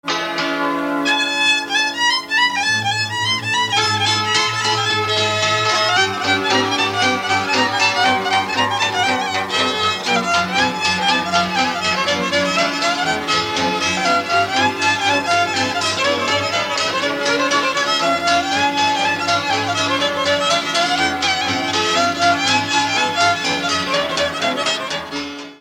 Dallampélda: Hangszeres felvétel
Erdély - Udvarhely vm. - Zetelaka
hegedű
kontrahegedű
cimbalom
bőgő
Műfaj: Marosszéki (forgatós)
Stílus: 4. Sirató stílusú dallamok